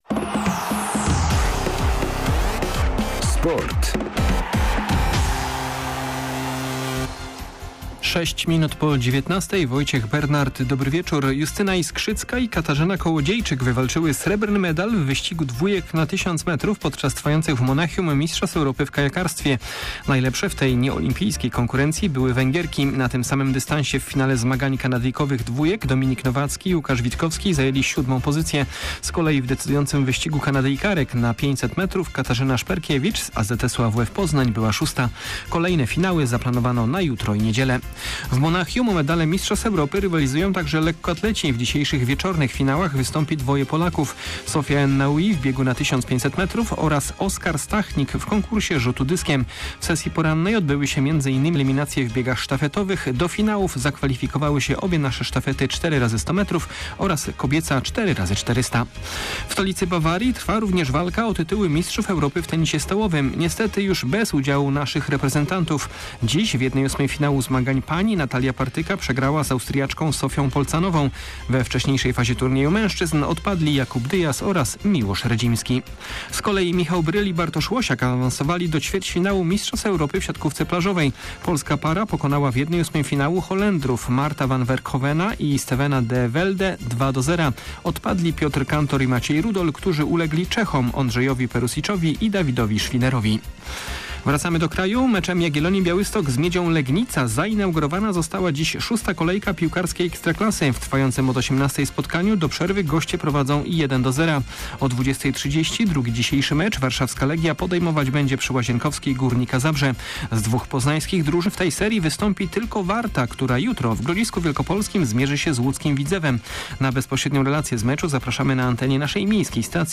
19.08.2022 SERWIS SPORTOWY GODZ. 19:05